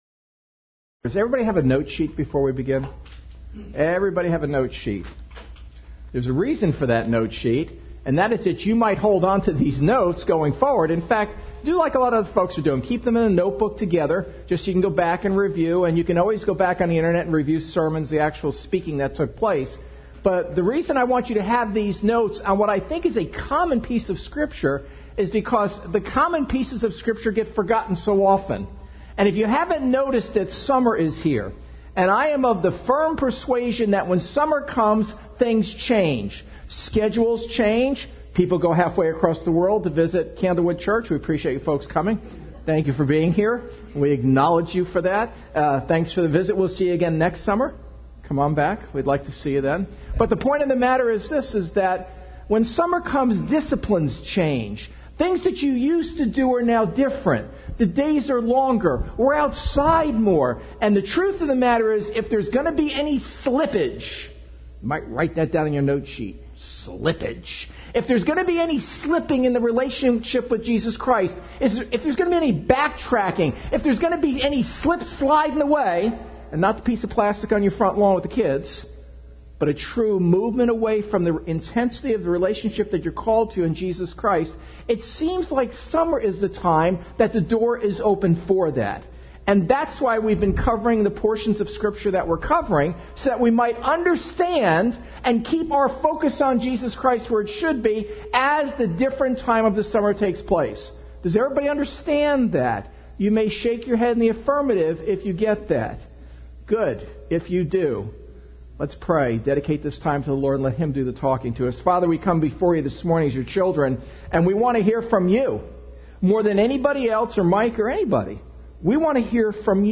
2019 Sometimes it Hurts Preacher